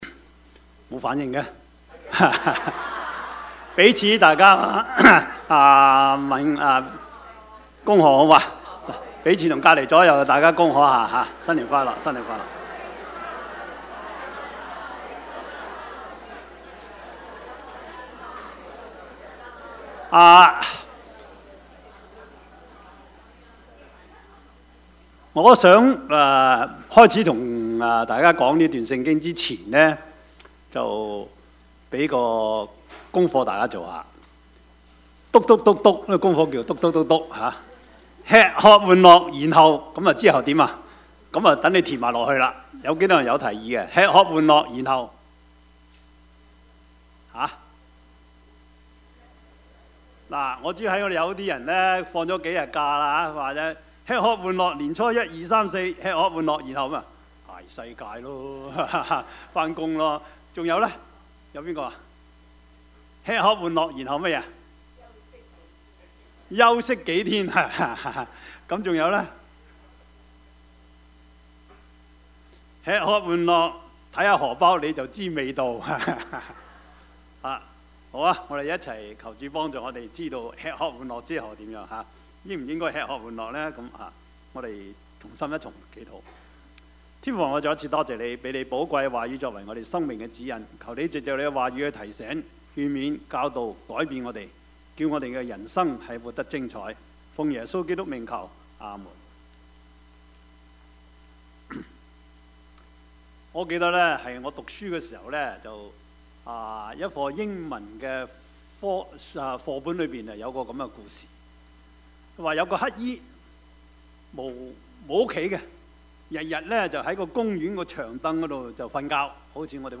Passage: 傳道書1:12-2:11 Service Type: 主日崇拜
Topics: 主日證道 « 你們要聽他 現代人的困惑 »